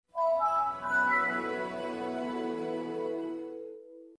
На этой странице собраны классические звуки Windows Vista — системные уведомления, мелодии запуска и завершения работы, а также другие аудиоэлементы ОС.
Звук загрузки Windows Vista